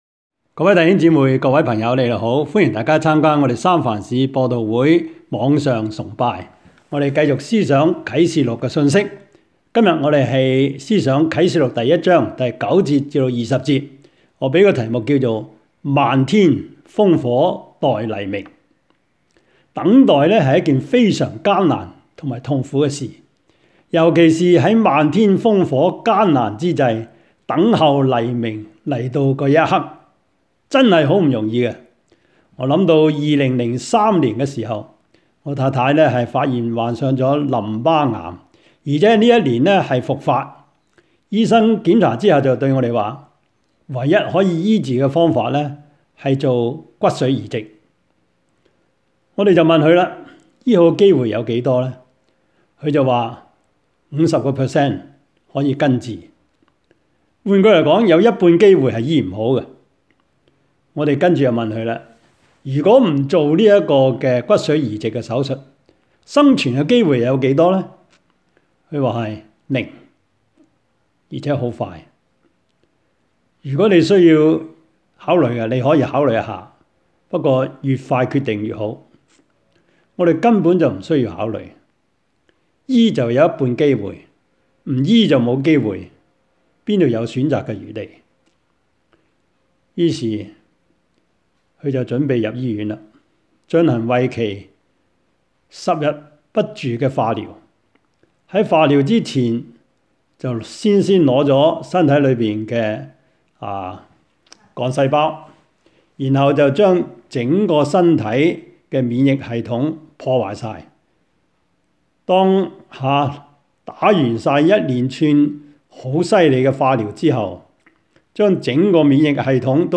Service Type: 主日崇拜
Topics: 主日證道 « 主禱文 合作?